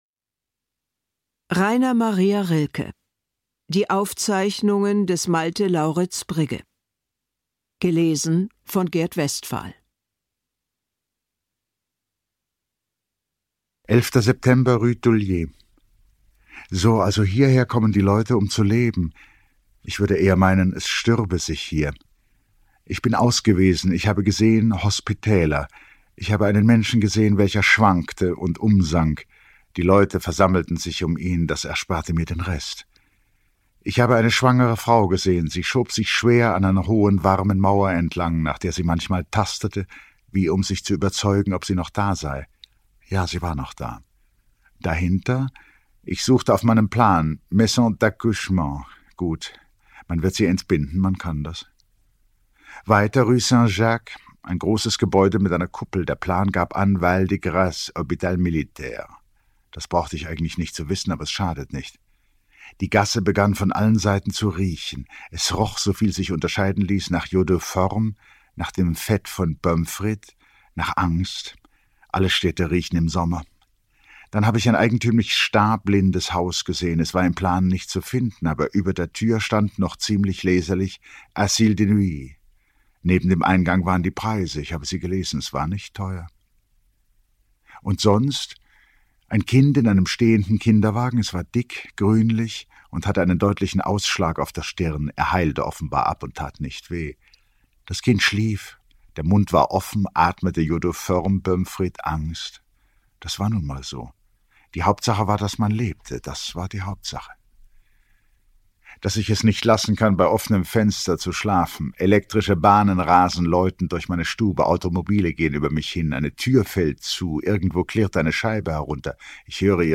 Lesung mit Gert Westphal (1 mp3-CD)
Gert Westphal (Sprecher)